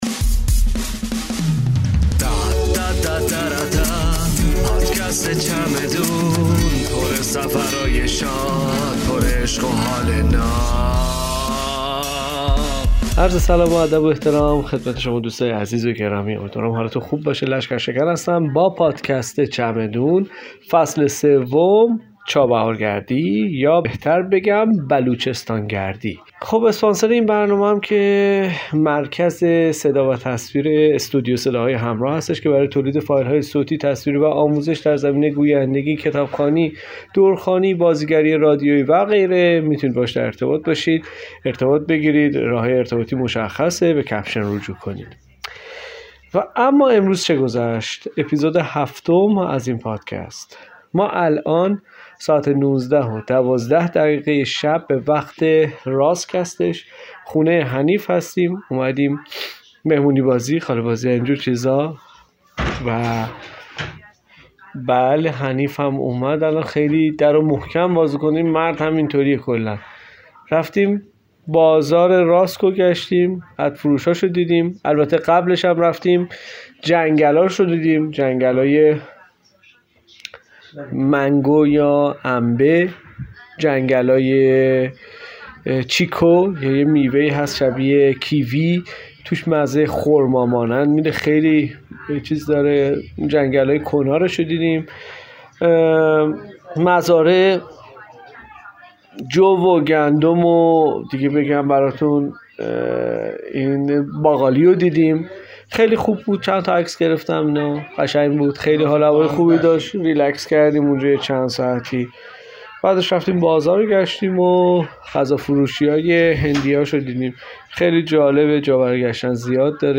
ضبط شده در سفر بصورت لایو